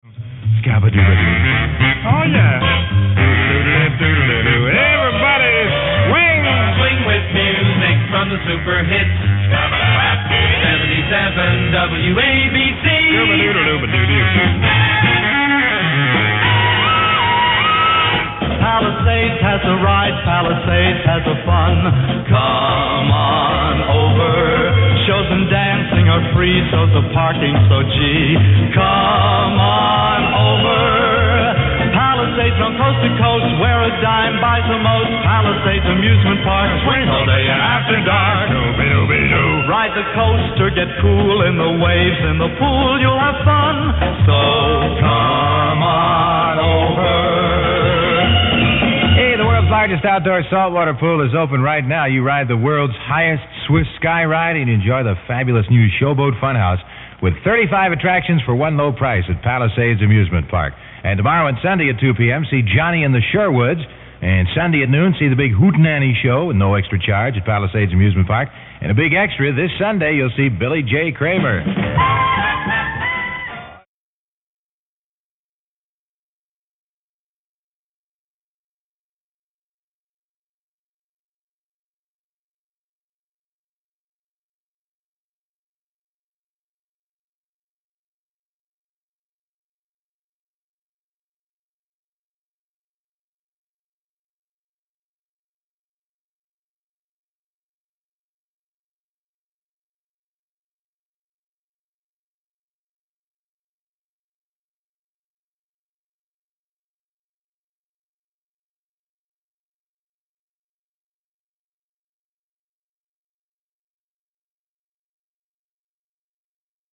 WABC commercial read by Dan Ingram - 1964